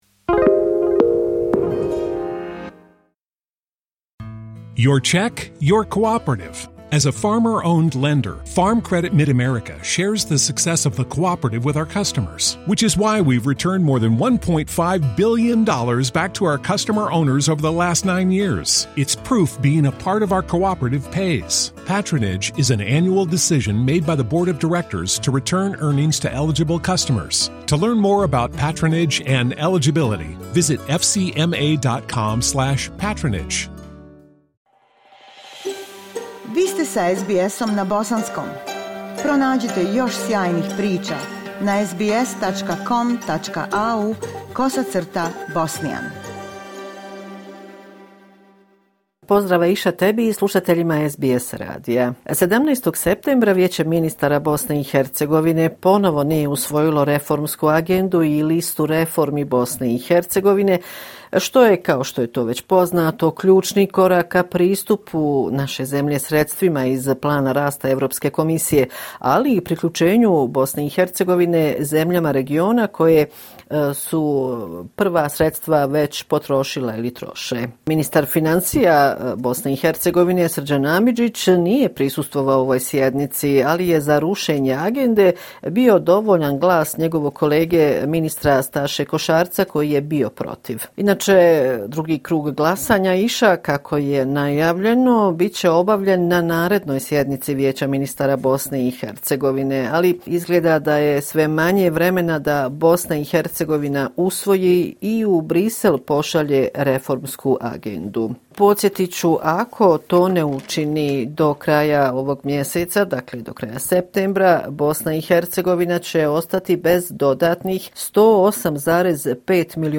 Pregled najvažnijih dešavanja na političkoj sceni u BiH tokom protekle sedmice. Redovni sedmični izvještaj iz Sarajeva